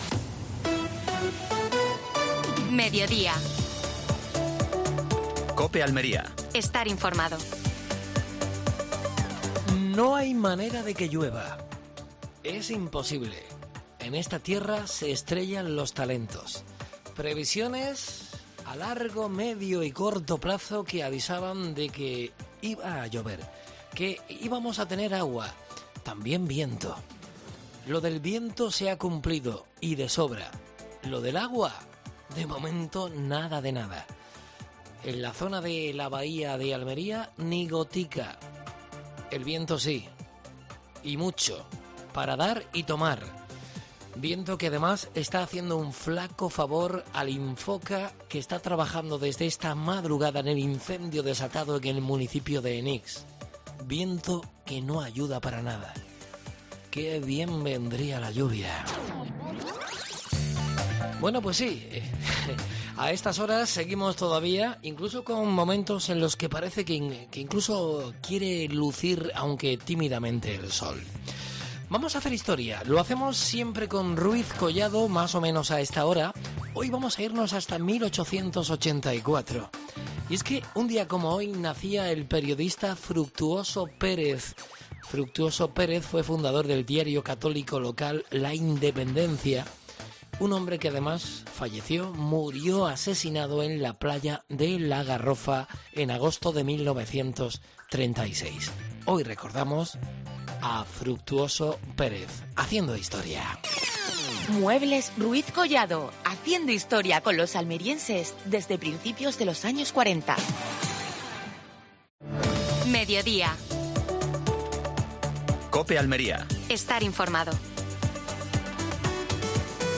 Actualidad en Almería. Fruit Logística desde Berlín. Entrevista